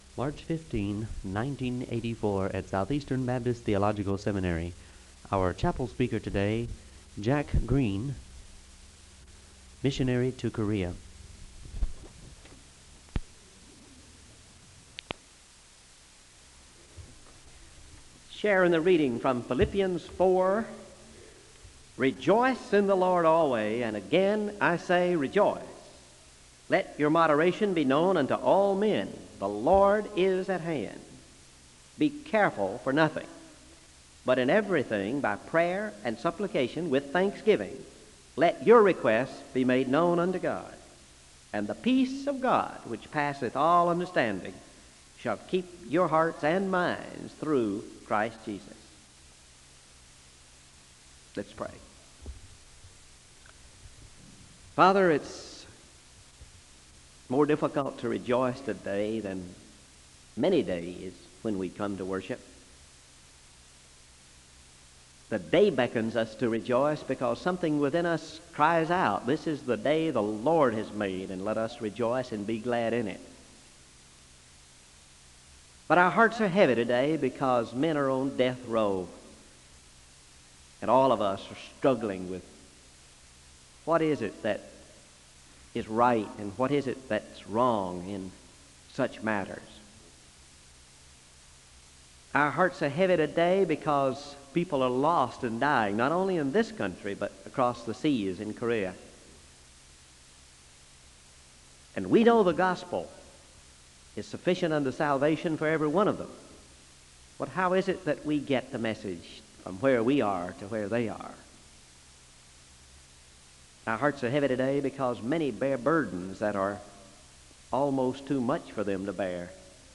The choir sings the anthem (05:11-08:53).
Wake Forest (N.C.)